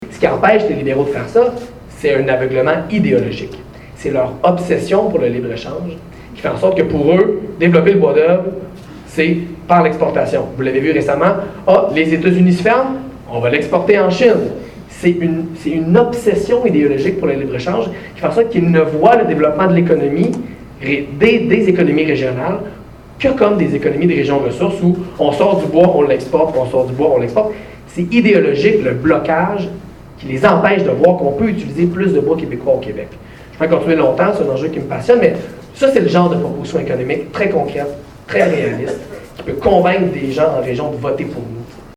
en entrevue avec Gabriel Nadeau-Dubois.